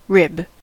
rib: Wikimedia Commons US English Pronunciations
En-us-rib.WAV